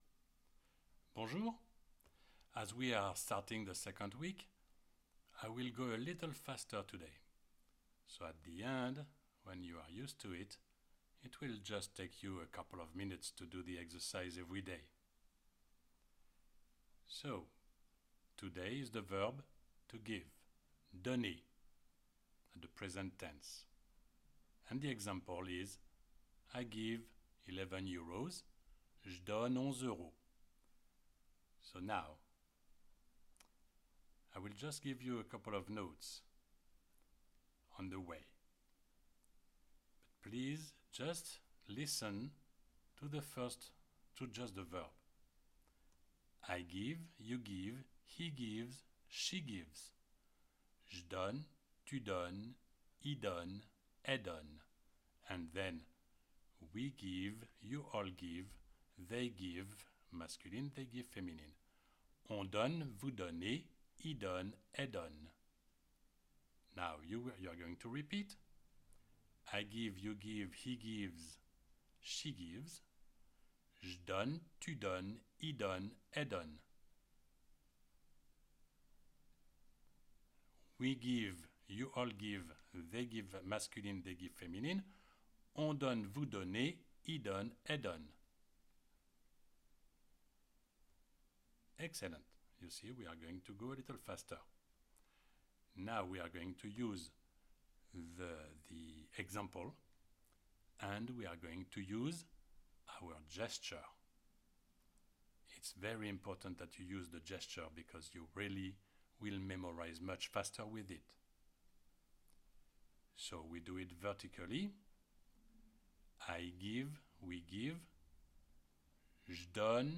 CLICK ON THE PLAY BUTTON BELOW TO PRACTICE ‘DONNER’, ‘TO GIVE’, IN THE PRESENT TENSE The classic French Conjugation Chart is not the best way to learn french verbs basic.
You read and you repeat with the audio.